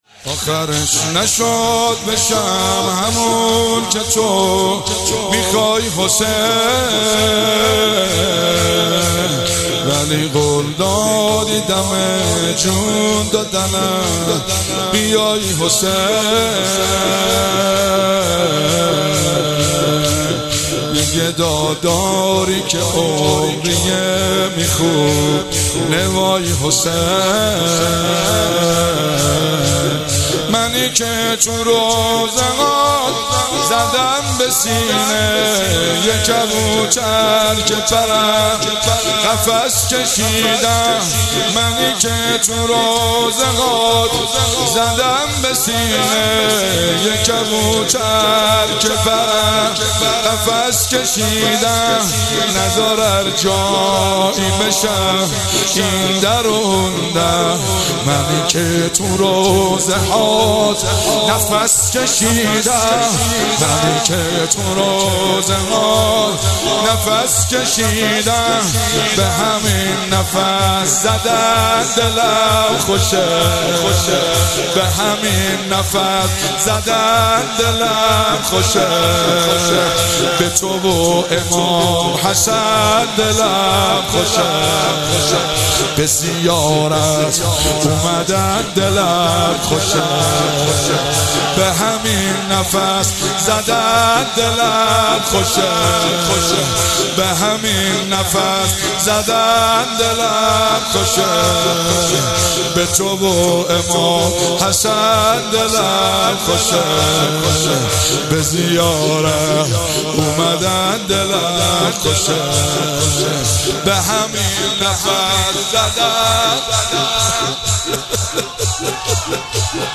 ایام فاطمیه۹۷ هیئت فاطمیون قم